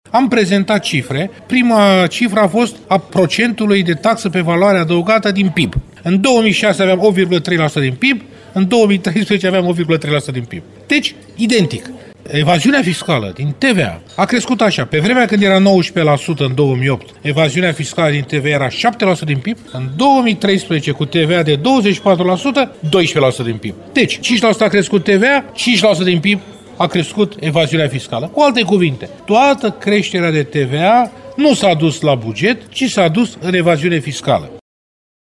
Creşterea TVA de la 19 la 24% nu a dus la încasări mai mari pentru stat, ci doar la creşterea evaziunii fiscale. Declaraţia a fost făcută astăzi, la Constanţa, de fostul ministrul al Economiei, Varujan Vosganian.